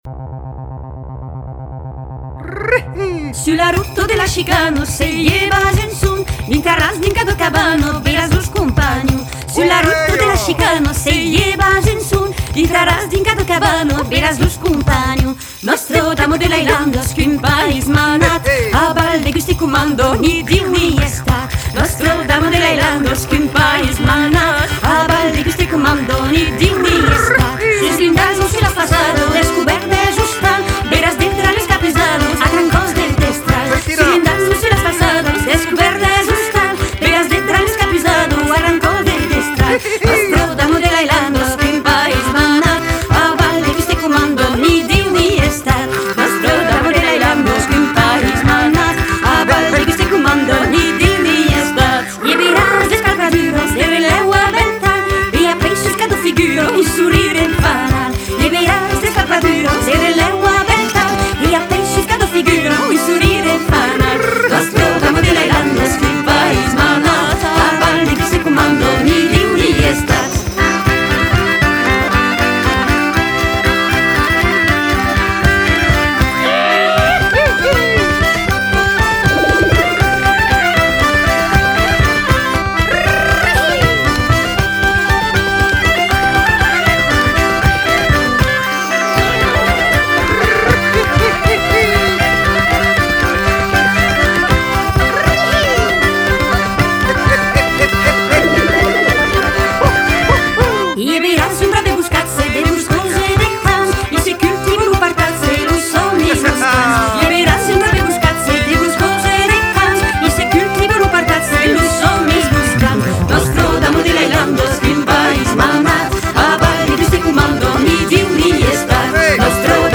Cants e musicas d’Occitania - Danse / Balèti
accordéon, cornemuses (craba, boha…), chant
chant, fifre, graile
clarinette
batterie, percussions